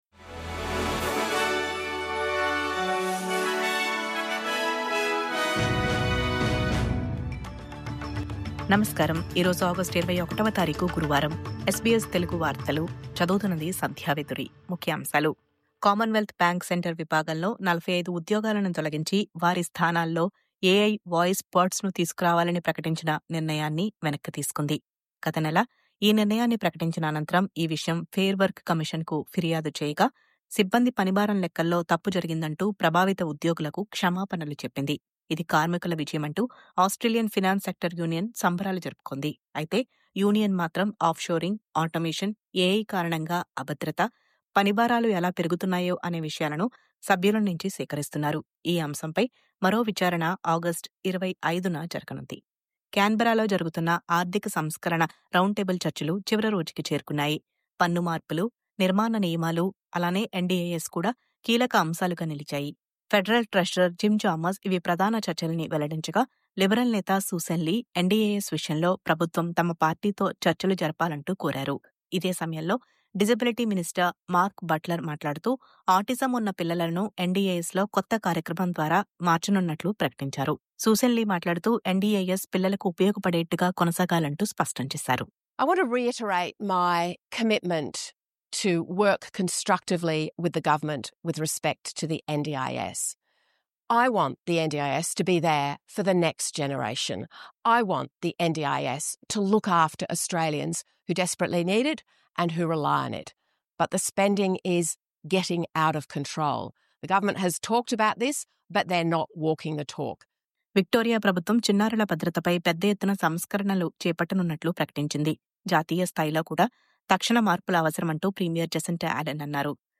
News Update:- చిన్నారుల భద్రతే లక్ష్యంగా… 22 సంస్కరణలను చేపడుతున్న విక్టోరియా ప్రభుత్వం..